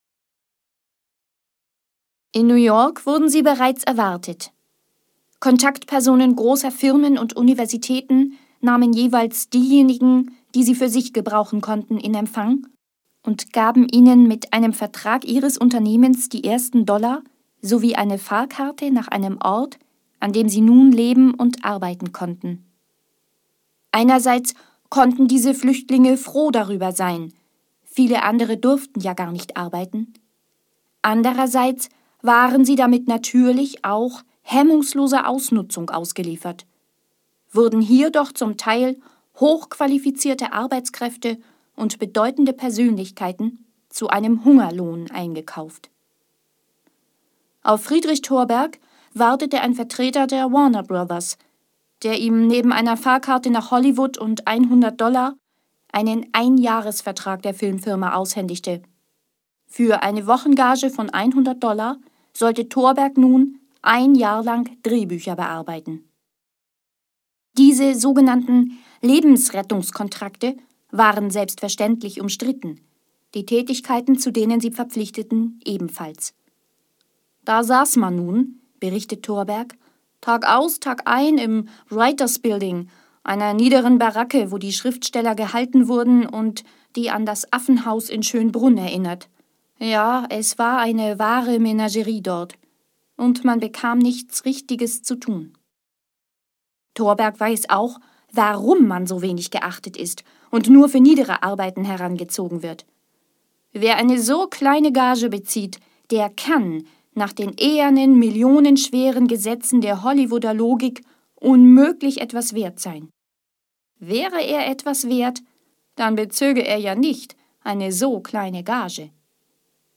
Hier sind einige Beispiele, wie es klingt, wenn ich aus meinen Texten etwas vorlese.